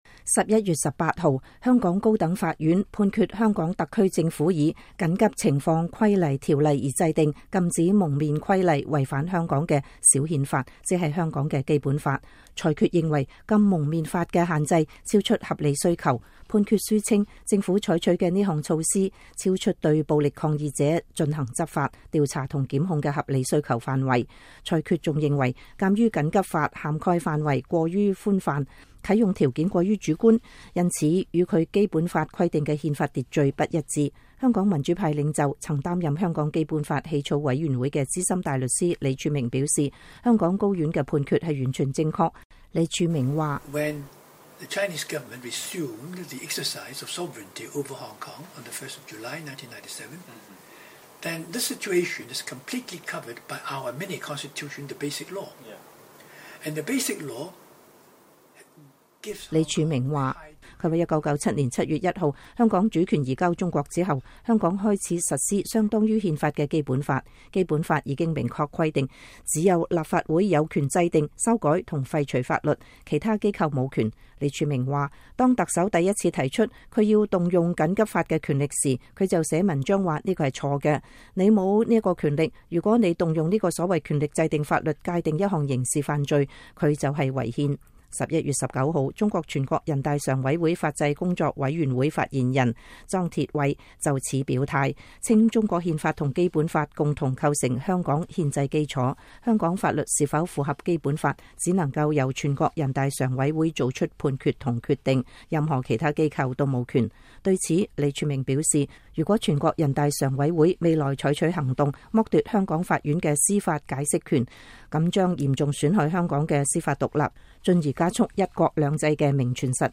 專訪李柱銘 - 香港步入警察政府高院判《禁蒙面法》違憲全正確